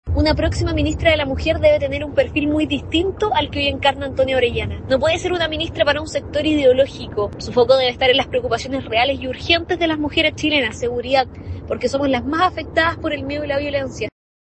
Desde republicanos, la diputada Chiara Barchiesi planteó que el nombre que arribe al ministerio debe priorizar las urgencias y no la ideología.